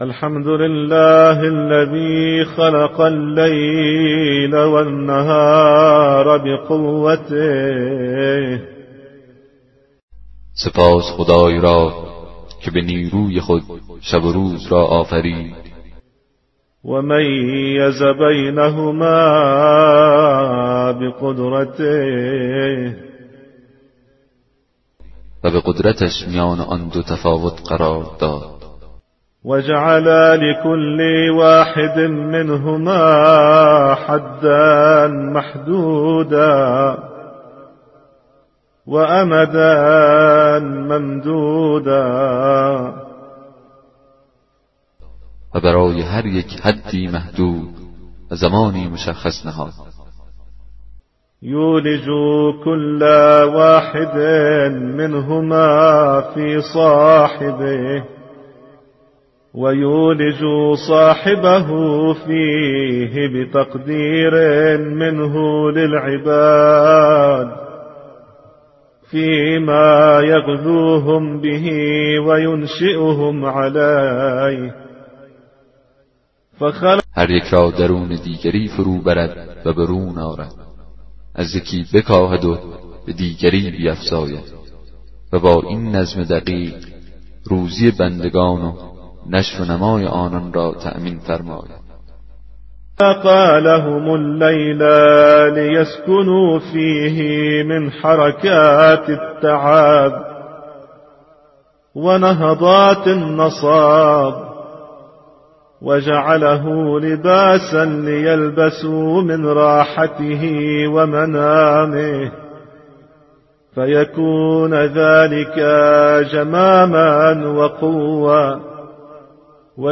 کتاب صوتی دعای 6 صحیفه سجادیه